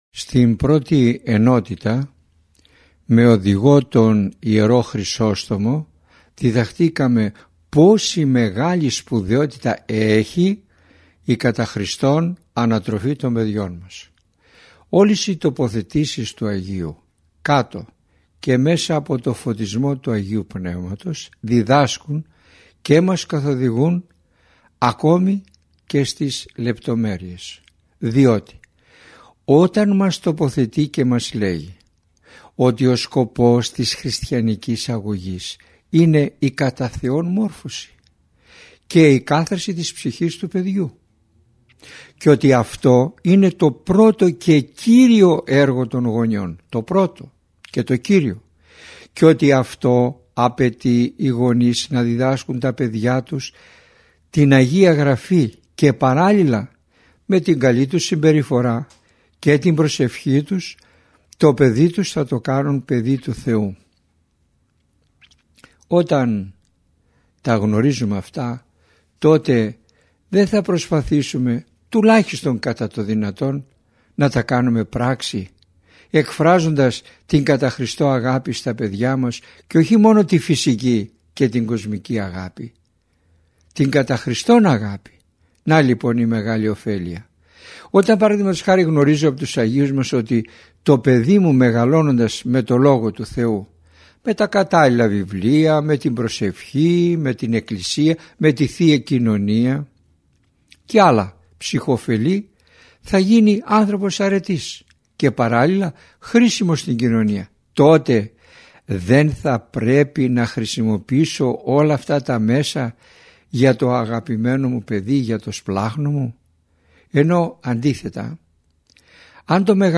Ακολούθως σας παραθέτουμε ηχογραφημένη ομιλία